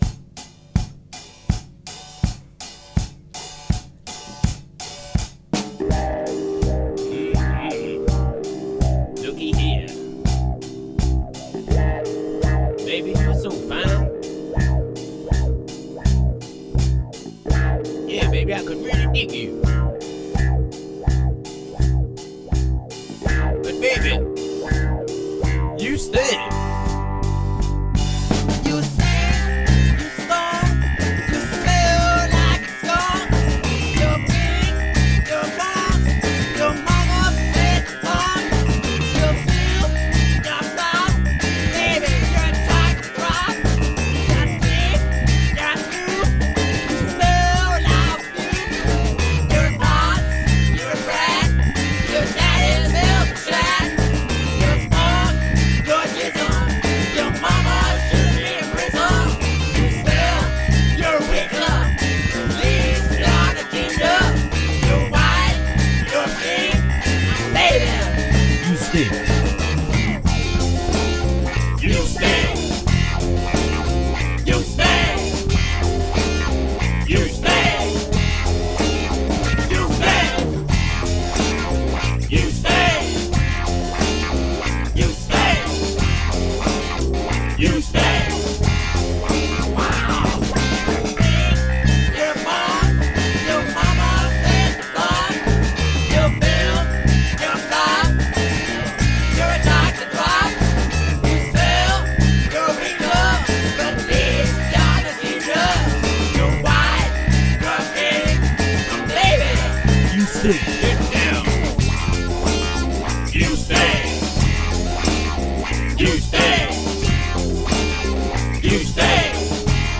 It’s also one of the few times I’ve lent my tone deaf vocals onto a song and been proud of it.